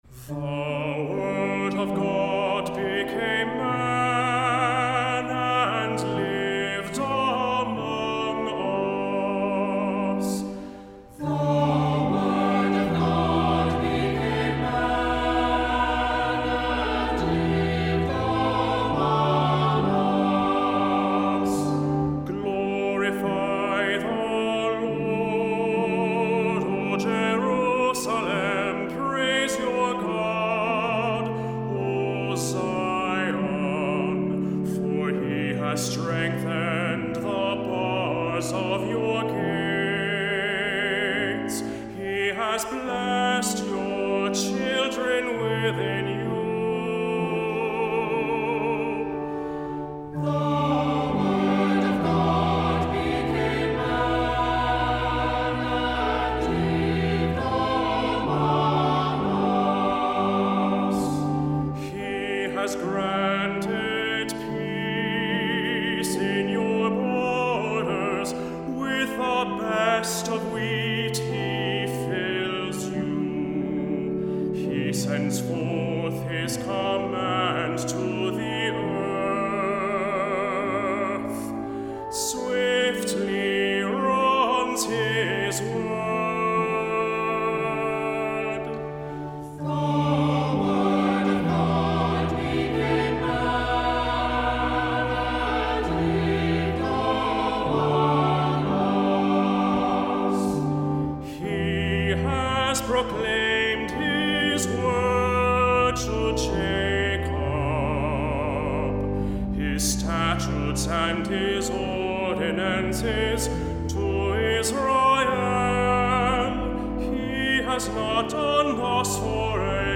Voicing: "SATB","Cantor","Assembly"